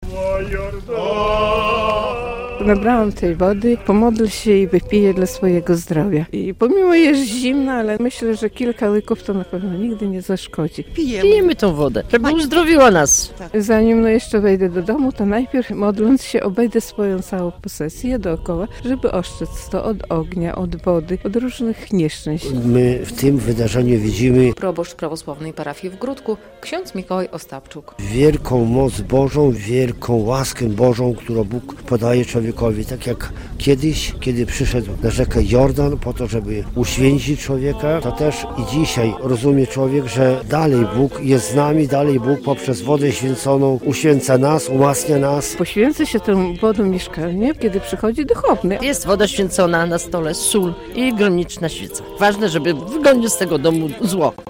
Święto Chrztu Pańskiego, uroczystości w Gródku - relacja